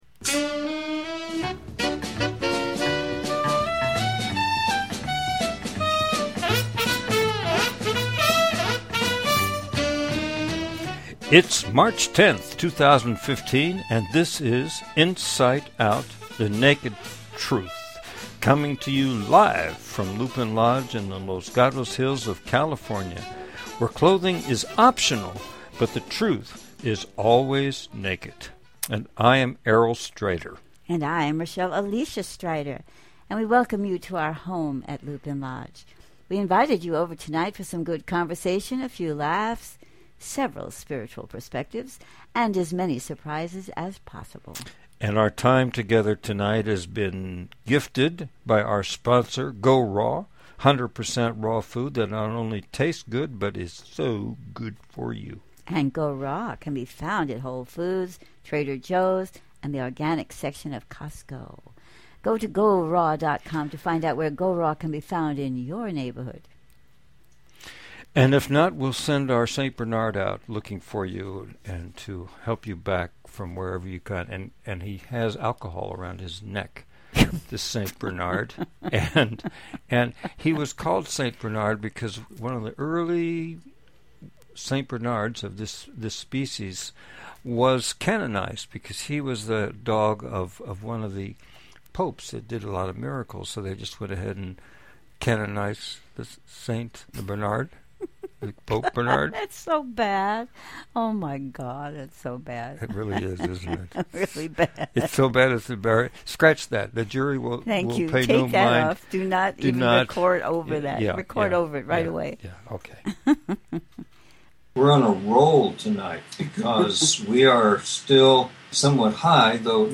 And in an effort to make our conversations more enjoyable, we use characters, share stories, listen to relevant songs and, hopefully, be surprised by new, heretofore, unrecognized dimensions of being and what we like to think of as cosmic punch lines.